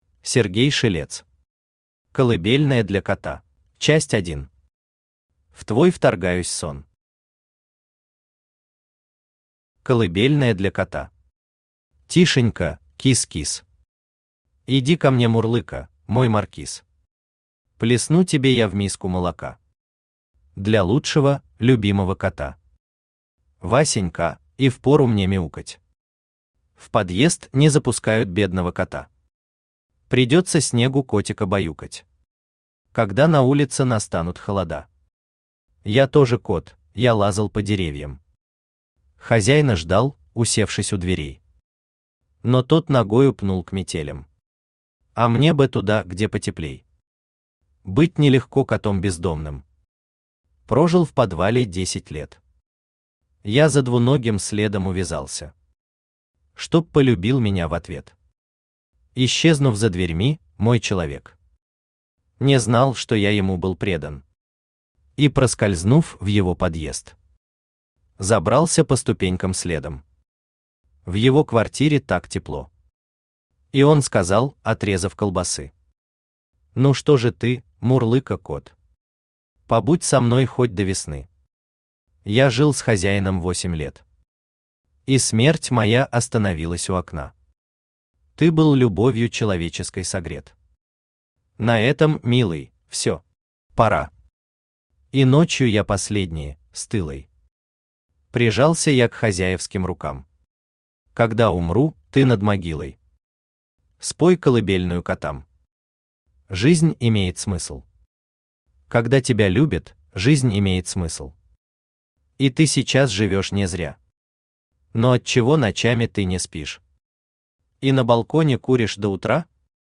Аудиокнига Колыбельная для кота | Библиотека аудиокниг
Aудиокнига Колыбельная для кота Автор Сергей Аркадьевич Шелец Читает аудиокнигу Авточтец ЛитРес.